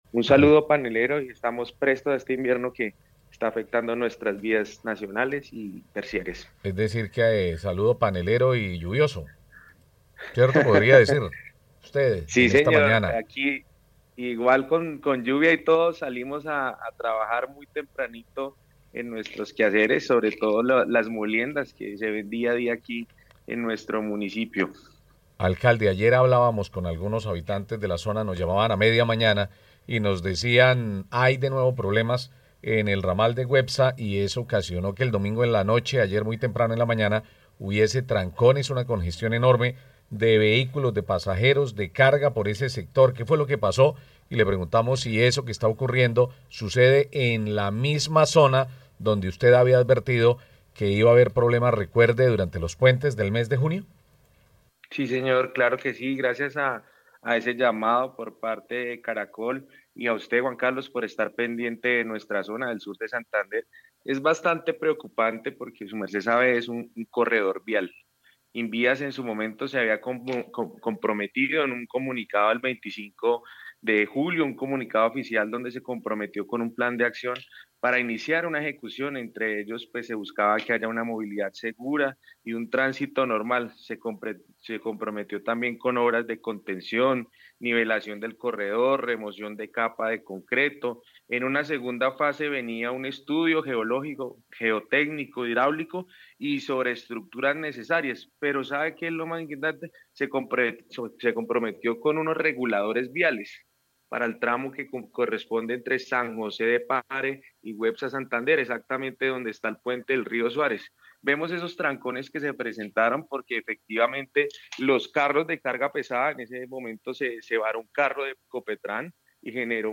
Hernando Fontecha Amado, Alcalde de Güepsa, Santander